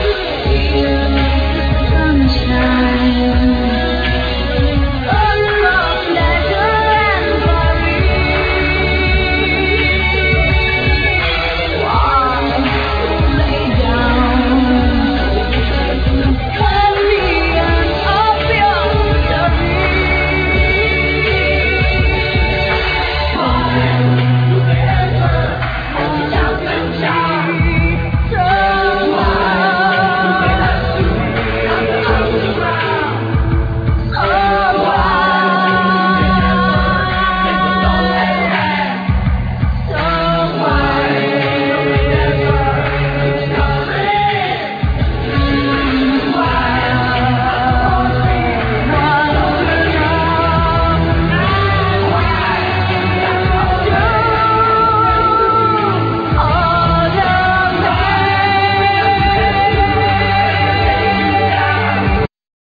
Guiats, Synthsizers, Drum machine, Vocal
Bass
Drums
Double bass
Piano, Synthesizers
Chorus